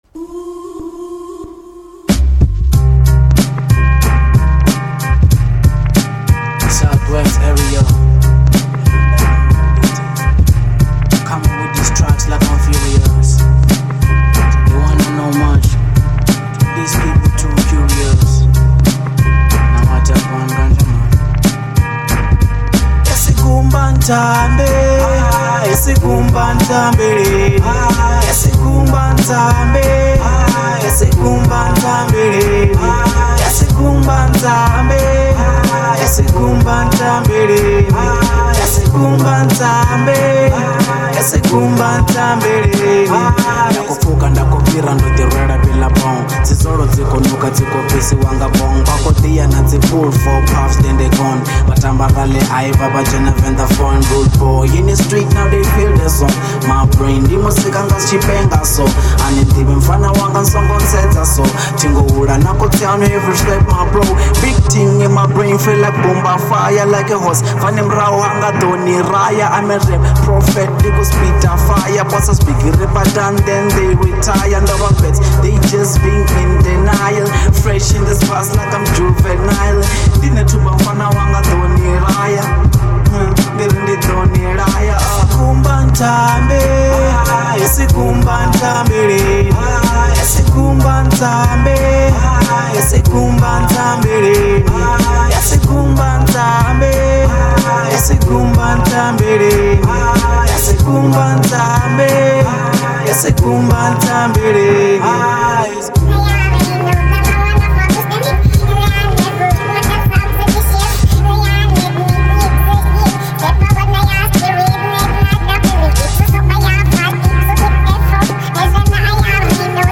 02:29 Genre : Venrap Size